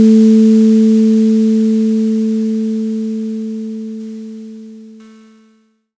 Generé una onda sinusoidal pura y la sometí a una Deriva Magnética simulada. Observé cómo la resolución se erosionaba en tiempo real. 16 bits. 8 bits. 4 bits. Hasta que la frecuencia fundamental fue irreconocible, consumida por su propio error de cuantificación.
Escucha cómo cambia el Grano.
El decaimiento digital es más frío. Es una pérdida de identidad entrecortada y dentada. Es el sonido de un recuerdo que se redondea al entero más cercano hasta que desaparece.